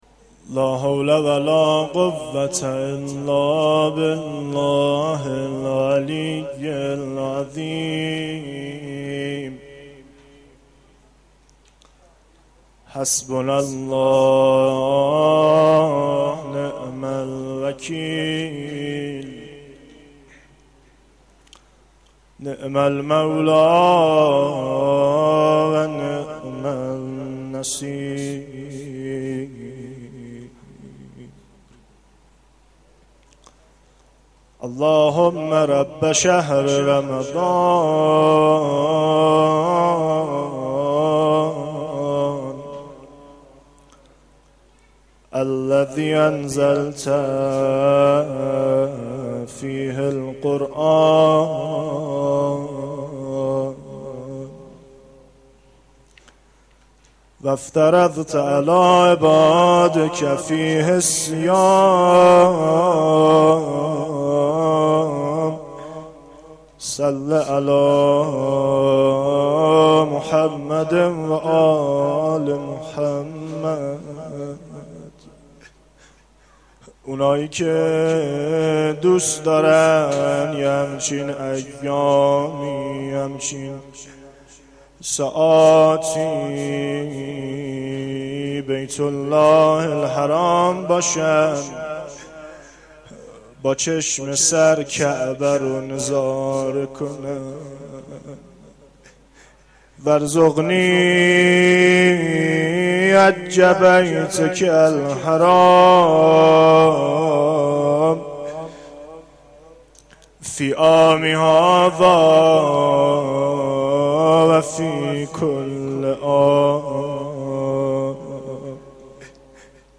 مناجات5
مداحی